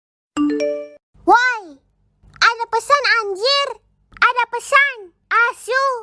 Nada notifikasi Woi ada pesan anjir
Kategori: Nada dering
nada-notifikasi-woi-ada-pesan-anjir-id-www_tiengdong_com.mp3